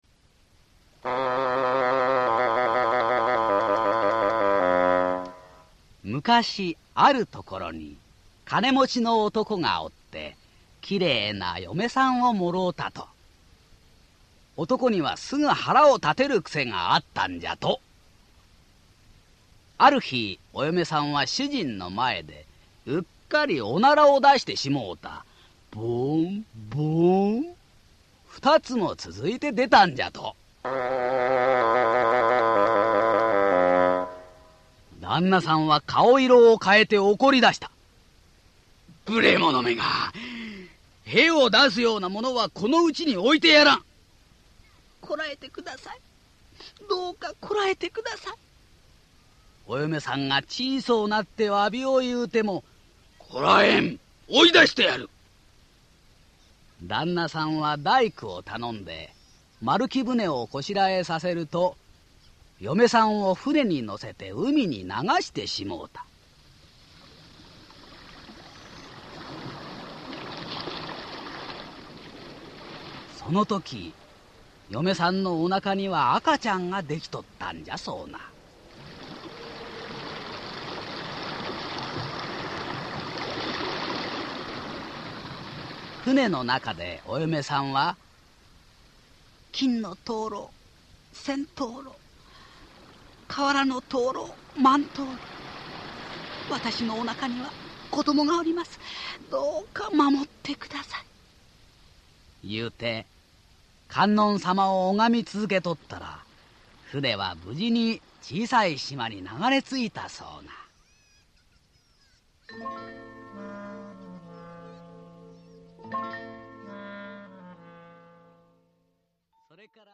[オーディオブック] 金のなる木